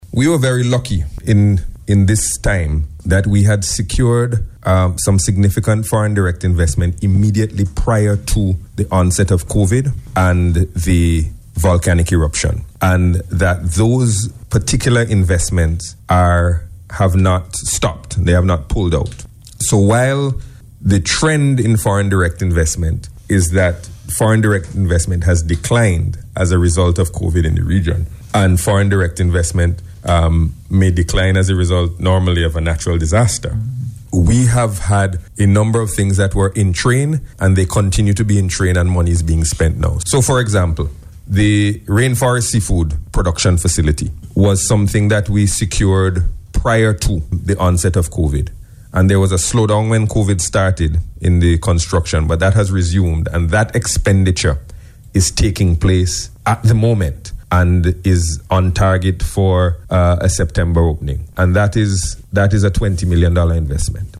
This assurance came from Finance Minister, Camillo Gonsalves, as he discussed progress in relation to Foreign Direct Investment while speaking on radio on on Sunday.